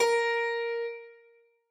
Harpsicord